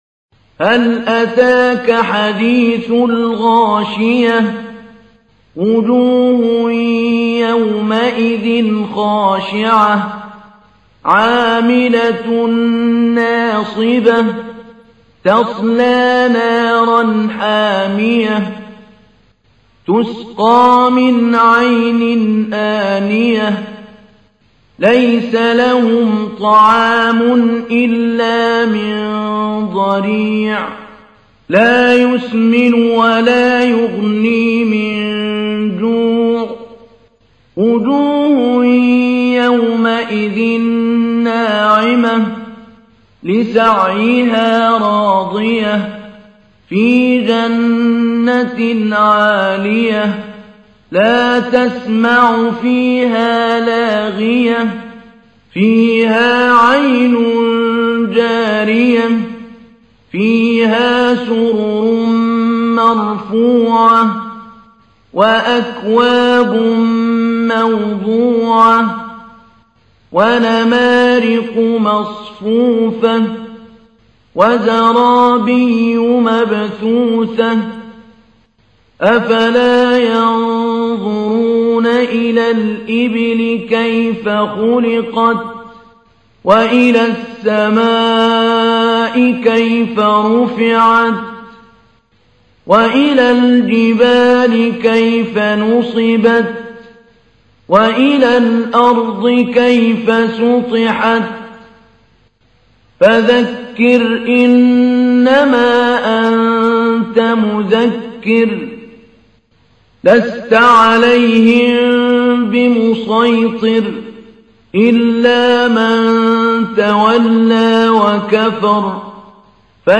تحميل : 88. سورة الغاشية / القارئ محمود علي البنا / القرآن الكريم / موقع يا حسين